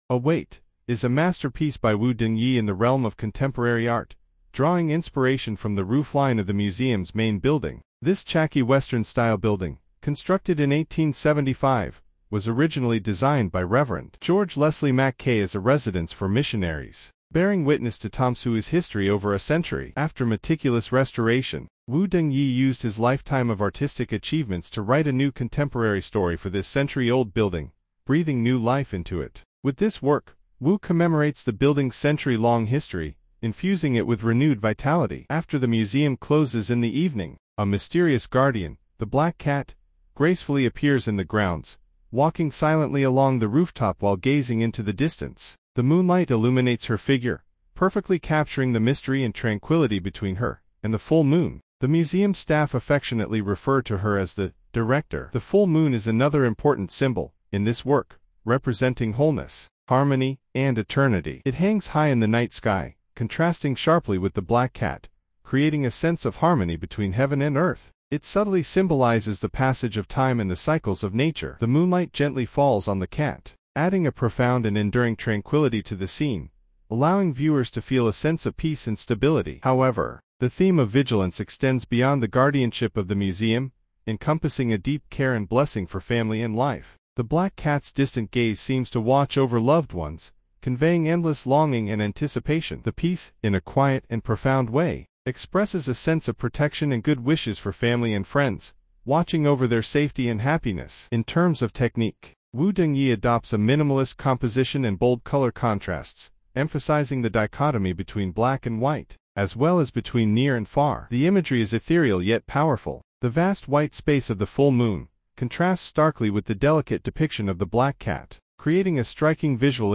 英文語音導覽